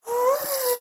mob / ghast / moan4.ogg
moan4.ogg